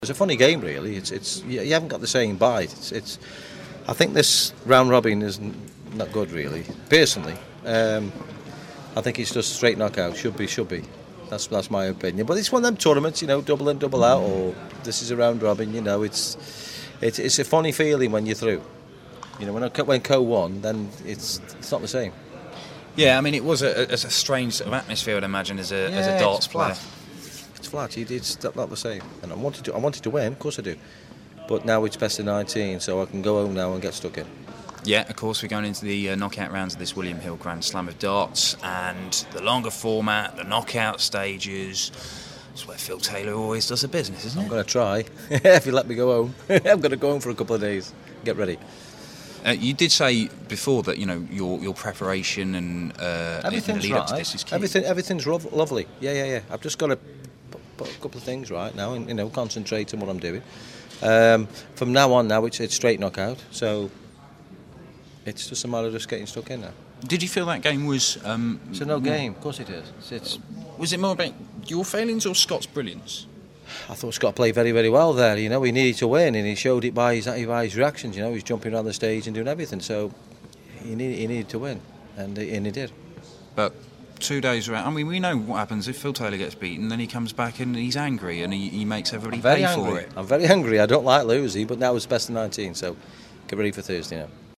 William Hill GSOD - Taylor Interview (3rd game)